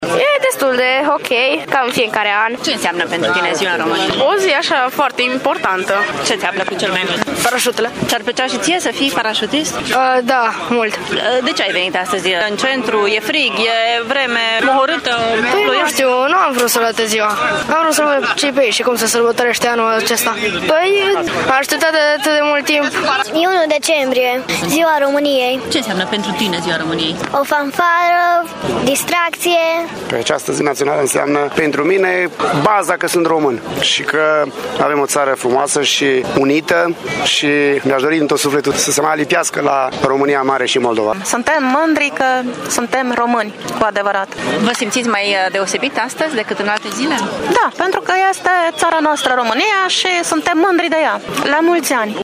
Deși astăzi a fost o zi rece și mohorâtă, târgumureșenii nu au stat în case și cu ieșit cu mic cu mare, de Ziua Națională, să viziteze expoziția de tehnică și intervenție militară.
Târgumureșenii cred că această zi ar trebui să trezească sentimente de bucurie pentru toți românii: